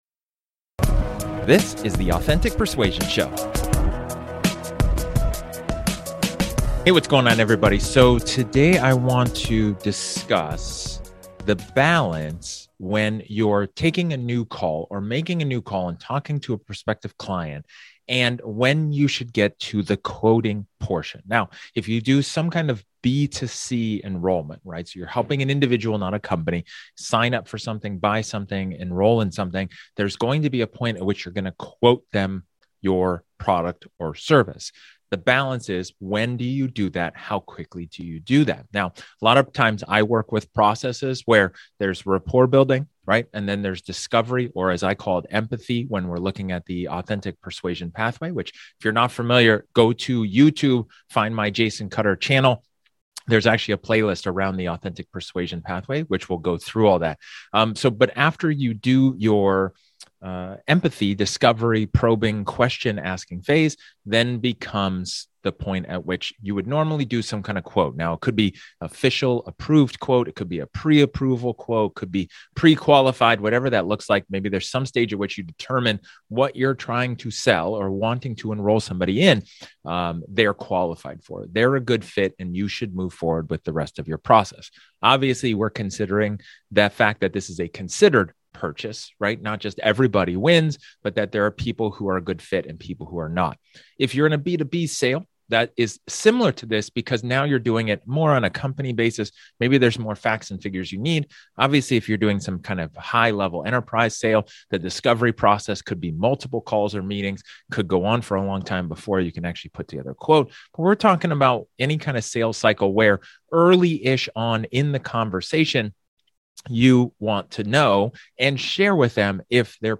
In this solo episode, I talk about getting to the quotation part – when do you do it and how quickly do you do it.